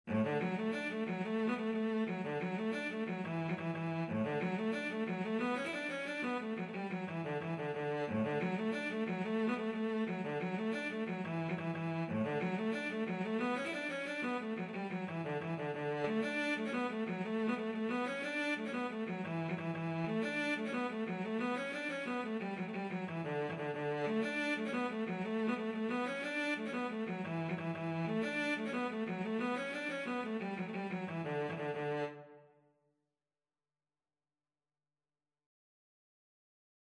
Cello version
D major (Sounding Pitch) (View more D major Music for Cello )
6/8 (View more 6/8 Music)
Cello  (View more Easy Cello Music)
Traditional (View more Traditional Cello Music)